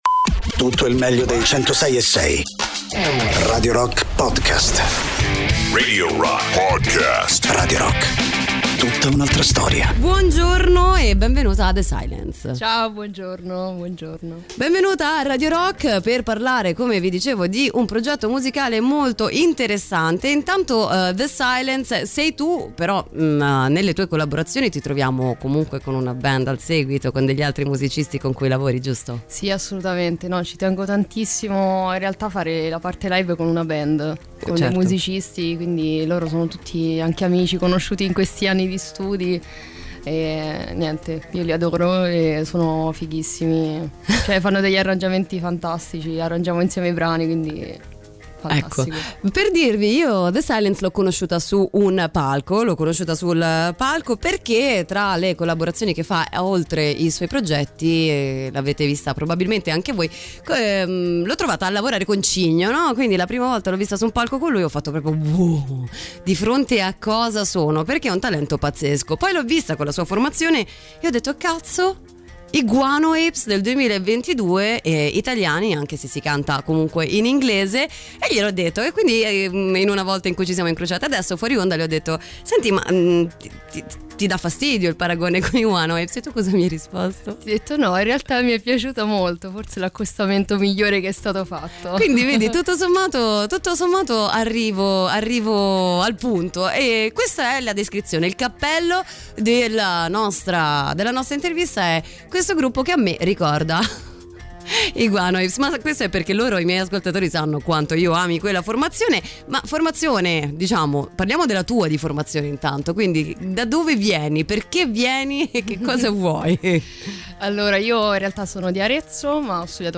Interviste: The Silence (23-10-22)